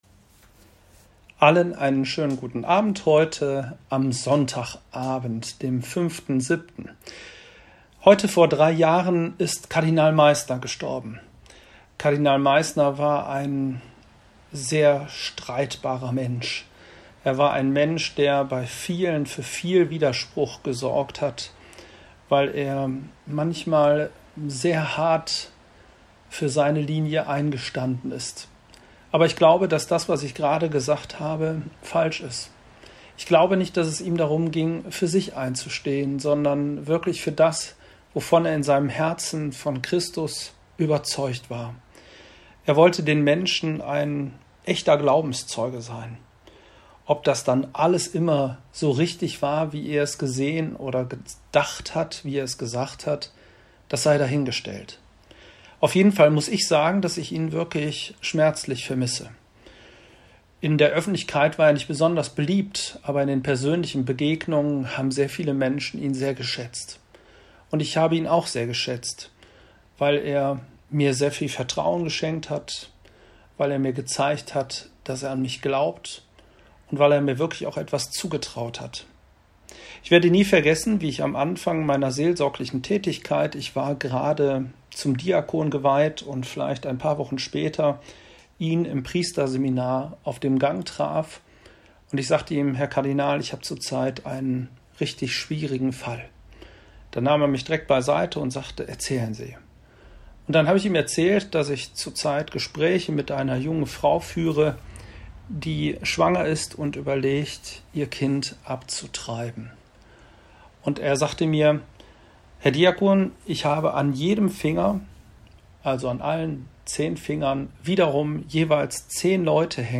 Predigt am 14. Sonntag im Jahreskreis A